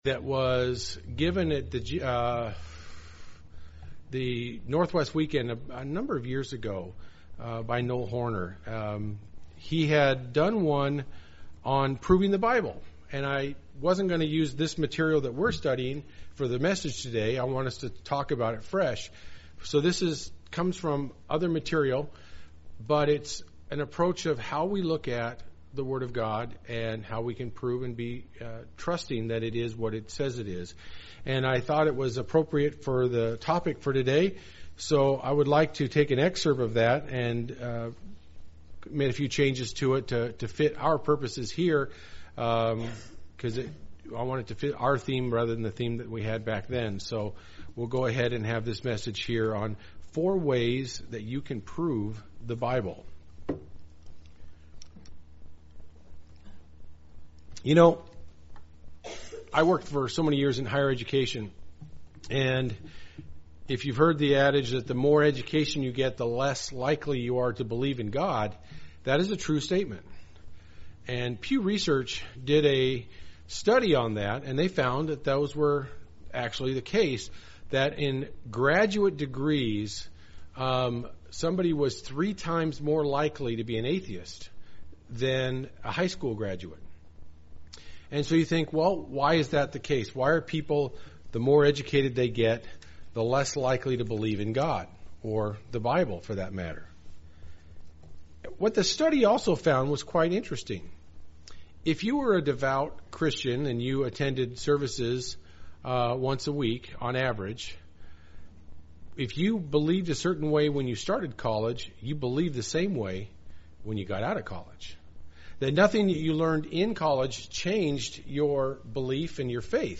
We are told that the word of God is something we can trust. This sermon looks at four ways you can prove the validity of the Bible.